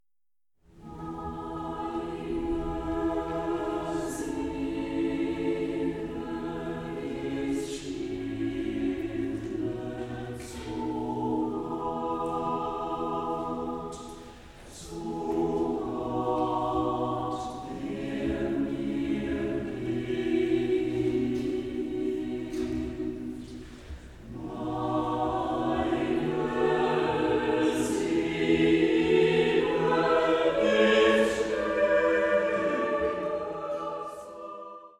Vokalensemble